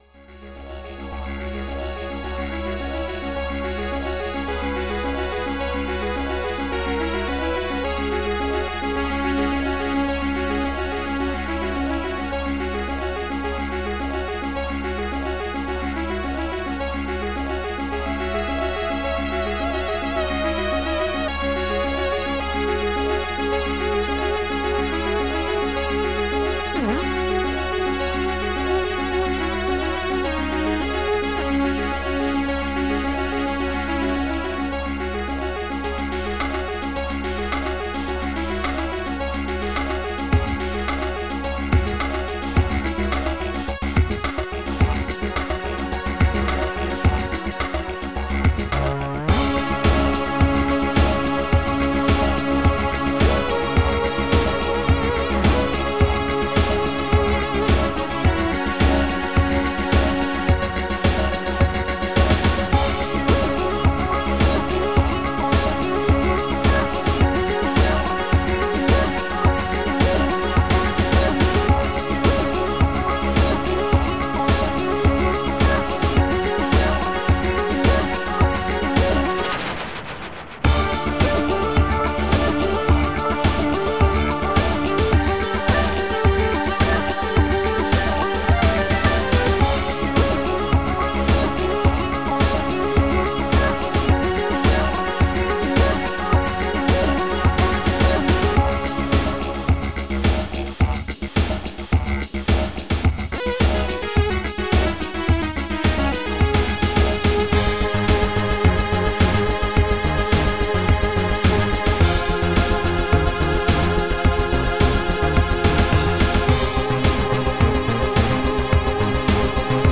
Protracker and family
ST-04:bassdrum.men1
ST-08:hihatop-boss
ST-11:rimshot.reverb
ST-08:bass.super
ST-10:fx.lazer1